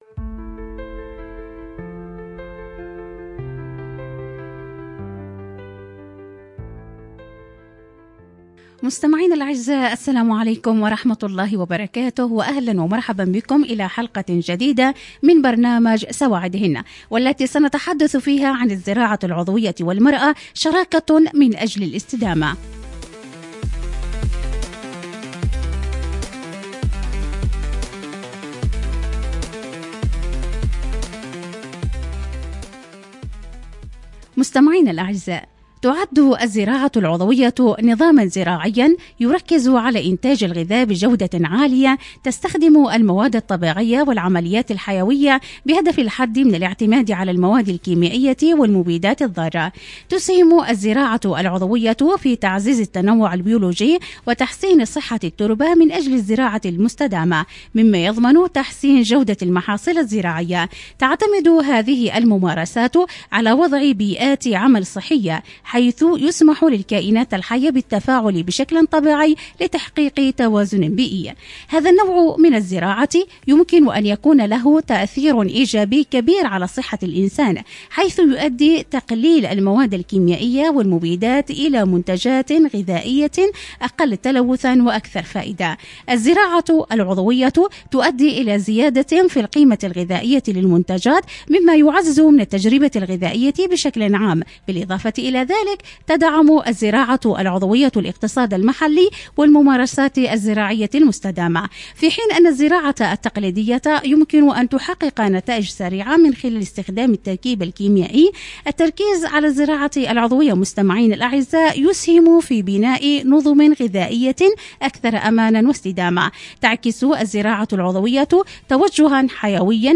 📻 المكان: عبر أثير إذاعة رمز